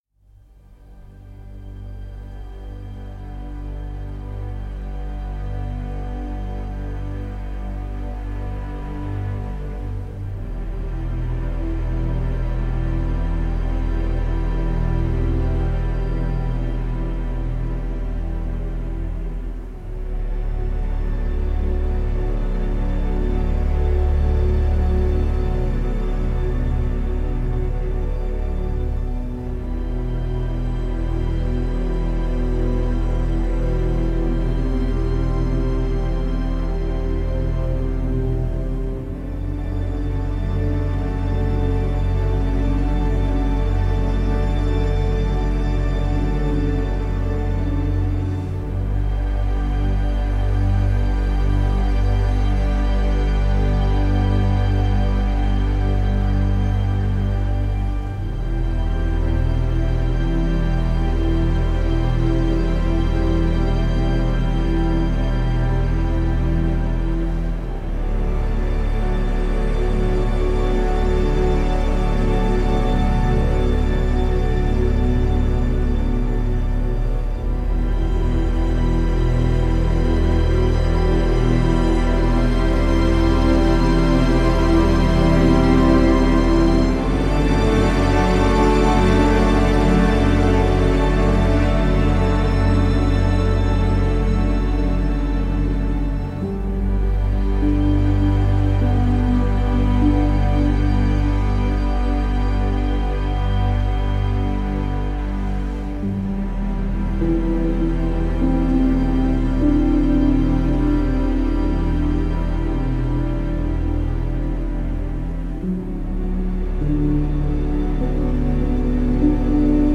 Жанр: Саундтреки / Саундтреки